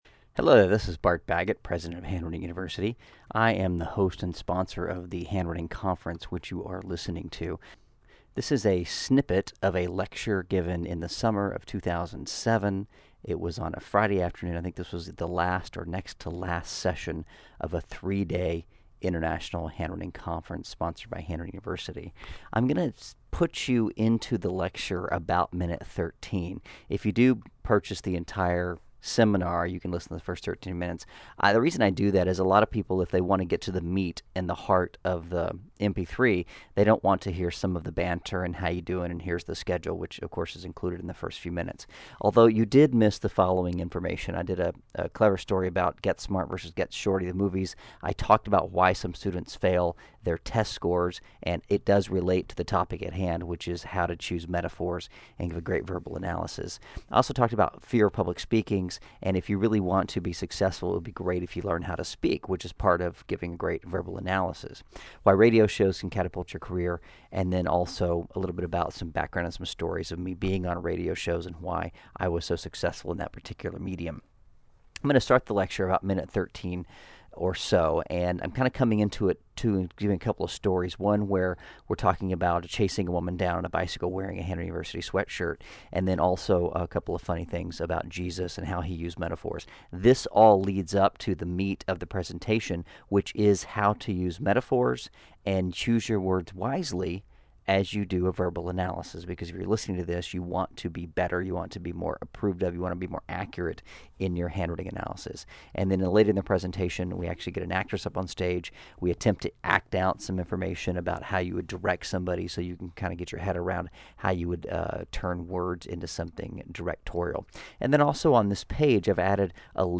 I just discovered 12 hours of a 2007 seminar which has been sitting on the hard-drive and not even for sale.